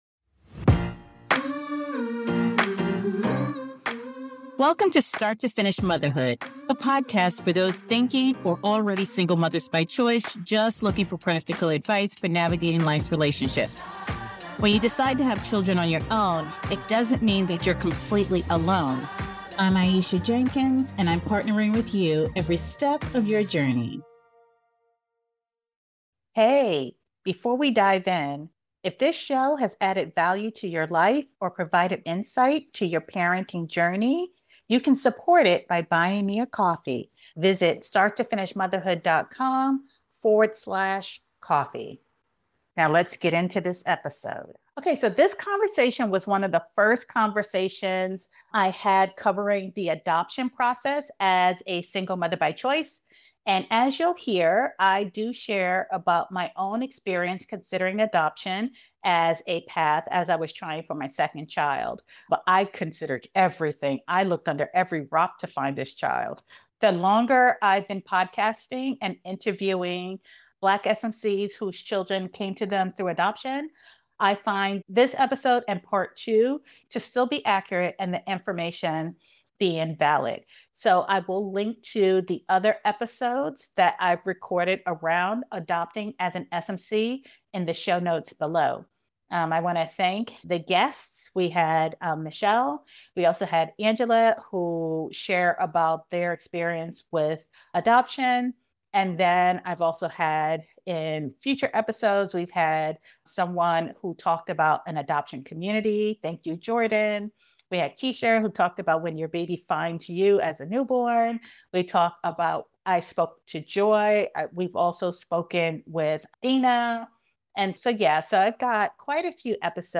This Mash-Up episode revisits a foundational conversation from Black Single Mothers by Choice, updated with reflection and insight from parenting school-age children.